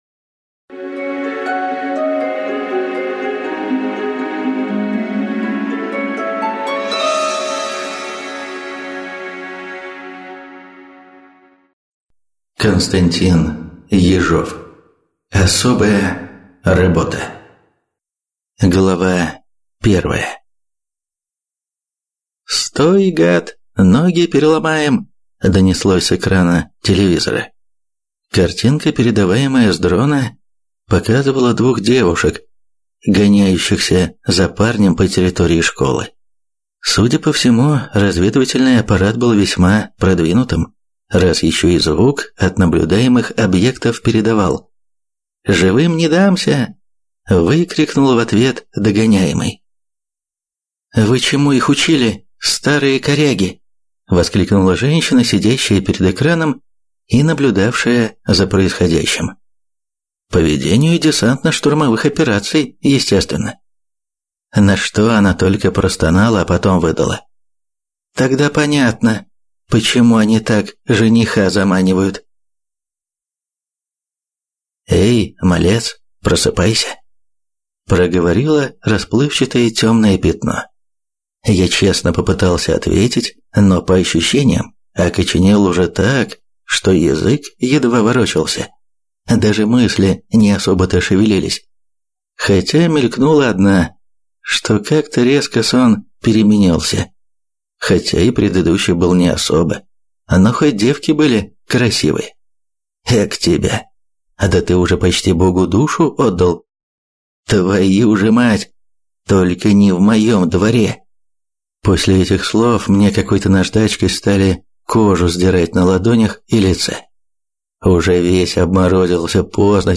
Аудиокнига Особая работа | Библиотека аудиокниг